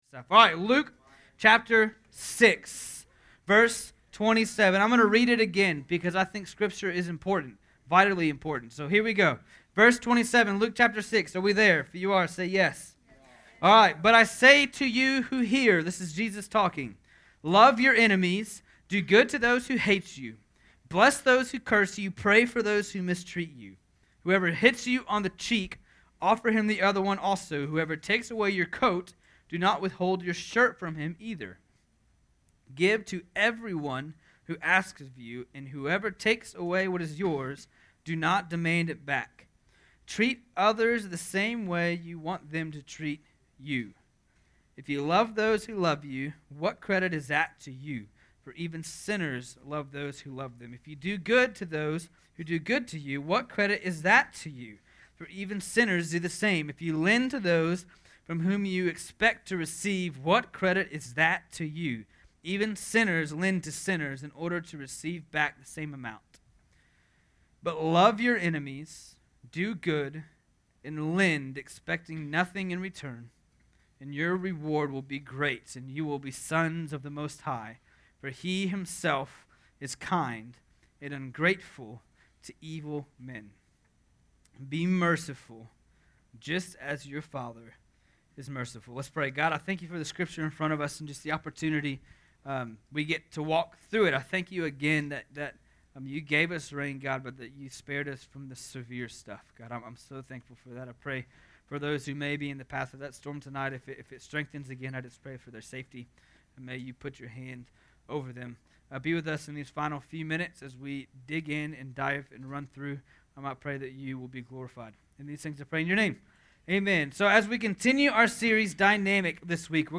Dynamic: Jesus Changes Social Relationships (Field Street Baptist Church Student Ministry